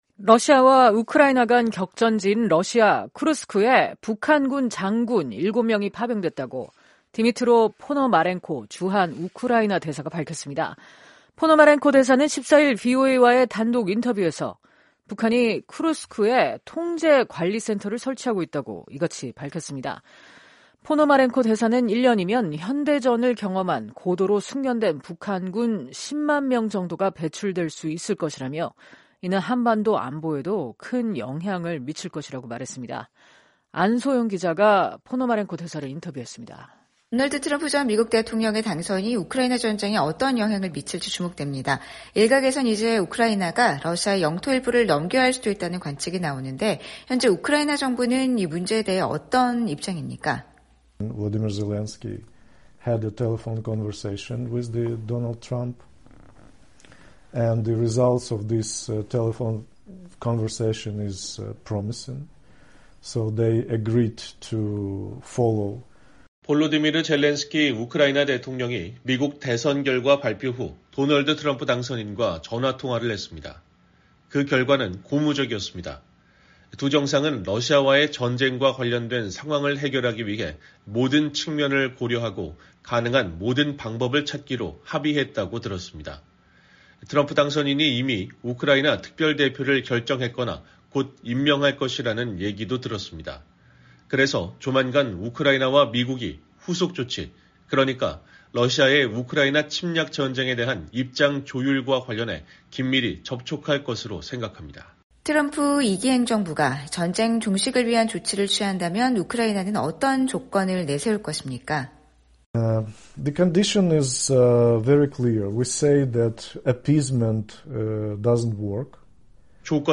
러시아와 우크라이나 간 격전지인 러시아 쿠르스크에 북한군 장군 7명이 파병됐다고 디미트로 포노마렌코 주한 우크라이나 대사가 밝혔습니다. 포노마렌코 대사는 14일 VOA와의 단독 인터뷰에서 북한이 쿠르스크에 통제 관리 센터를 설치하고 있다고 이같이 말했습니다. 포노마렌코 대사는 1년이면 현대전을 경험한 고도로 숙련된 북한군 10만 명 정도가 배출될 수 있을 것이라며, 이는 한반도 안보에도 큰 영향을 미칠 것이라고 말했습니다.